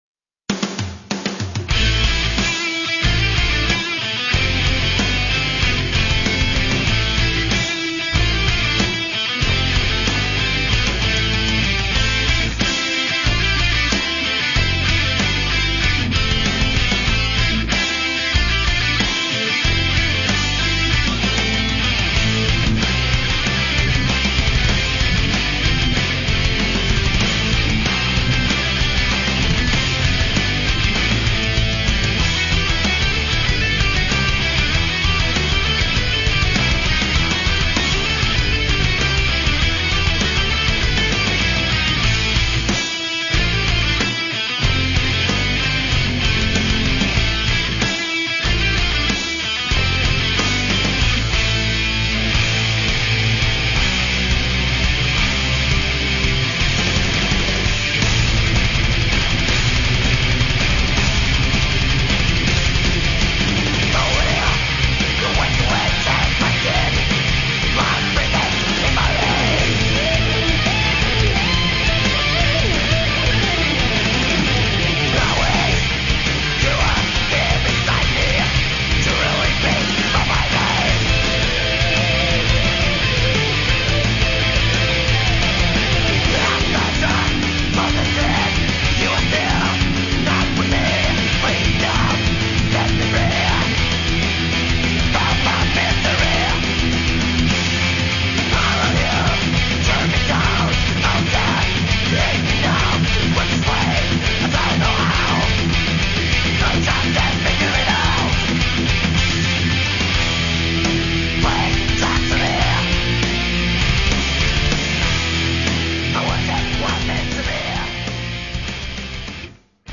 Все файлы размещены с качеством 32 Кбит/с, 22 кГц, моно